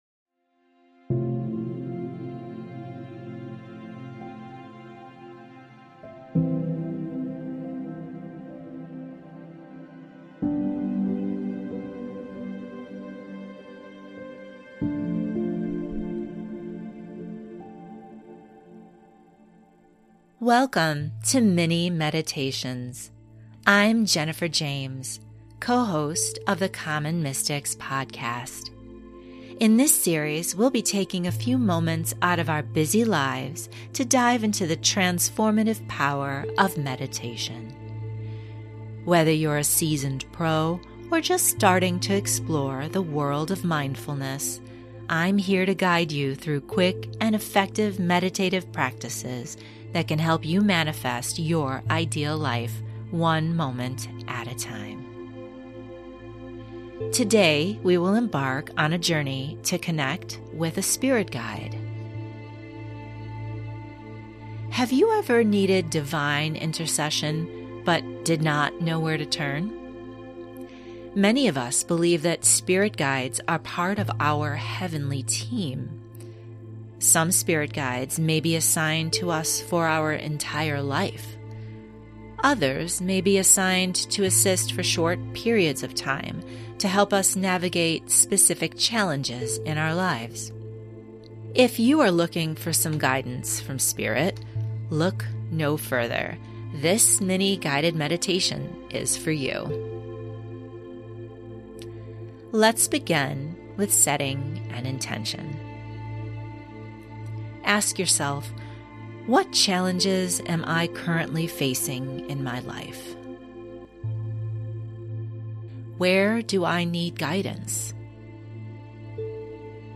Connect with them now using this 10 minute meditation.